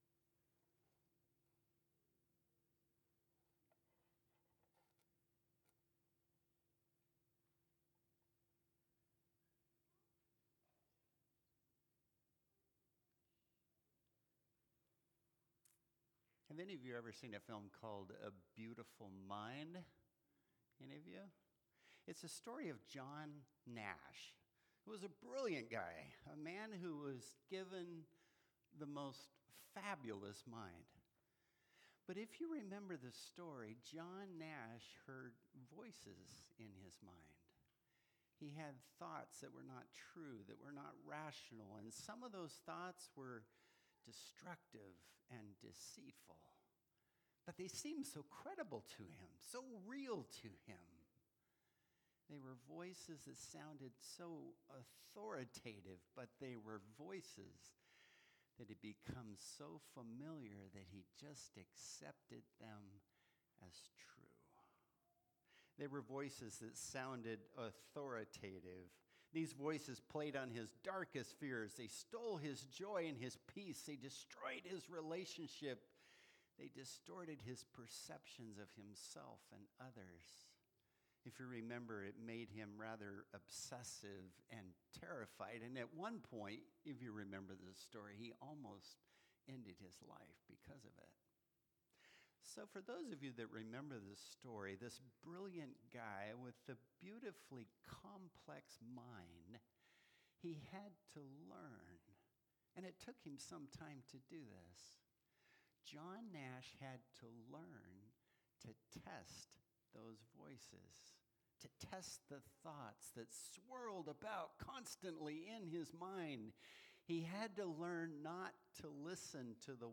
Series: Communions Service